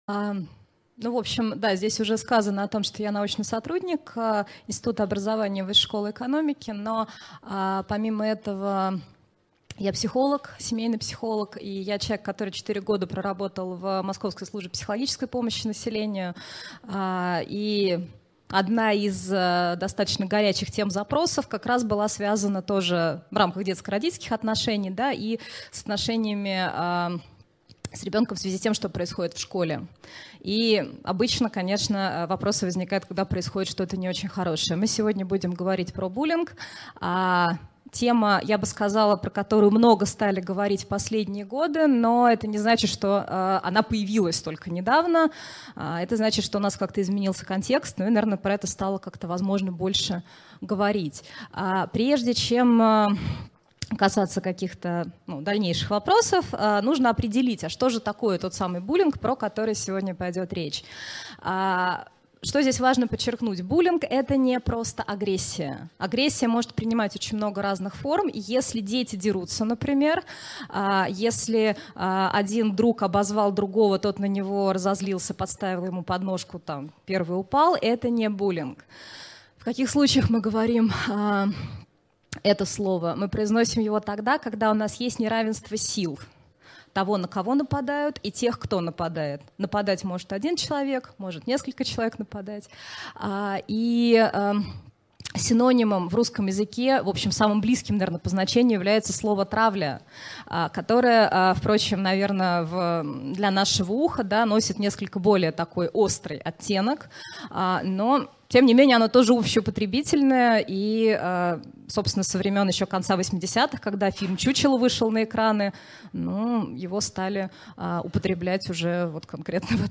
Аудиокнига Подростковый буллинг. Что делать и чего не делать родителю?